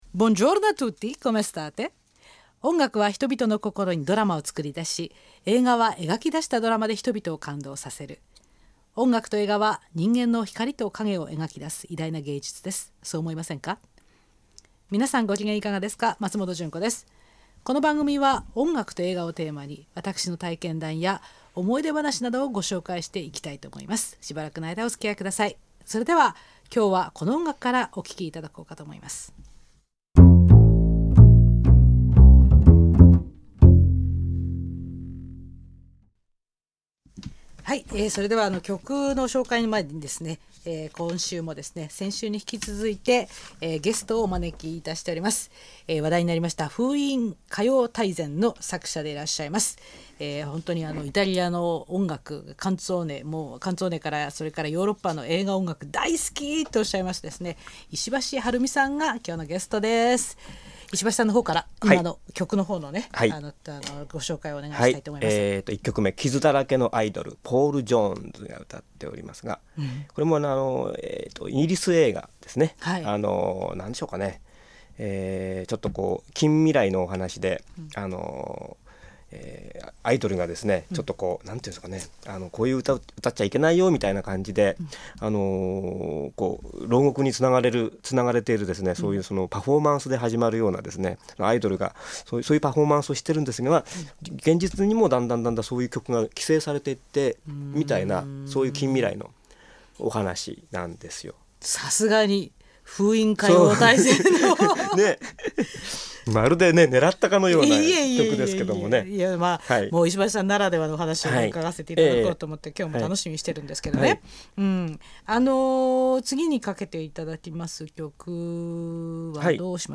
Les Aventuriers (1967) （冒険者たち） ※著作権保護のため楽曲部分は削除されておりますので、あらかじめご了承の上ご利用ください。